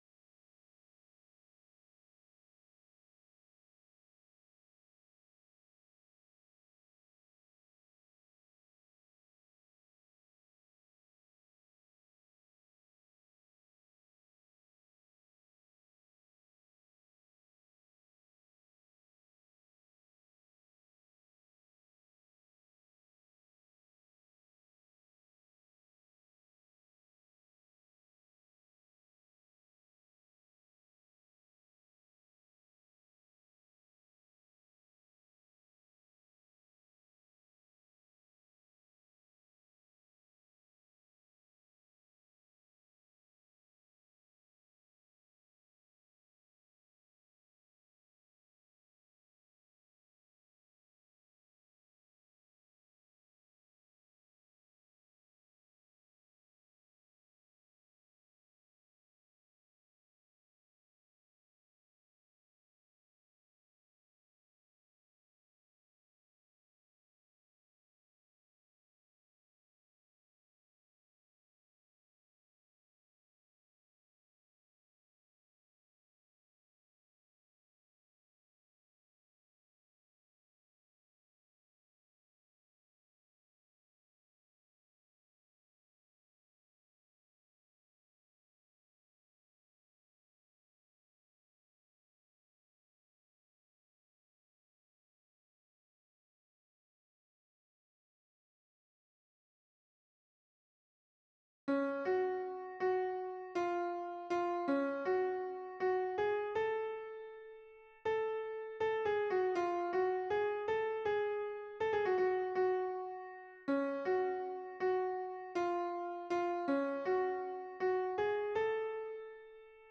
- berceuse traditionnelle norvégienne
MP3 version piano
Mezzo-soprano